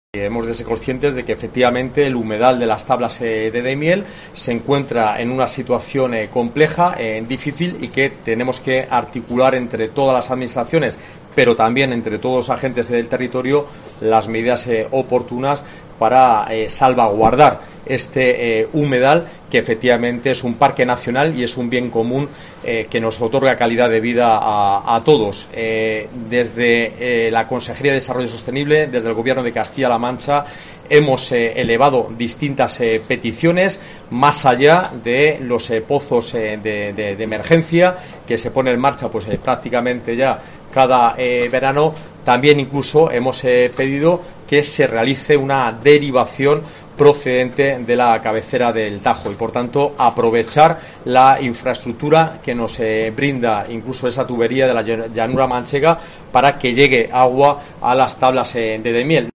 José Luis Escudero, consejero de desarrollo sostenible JCCM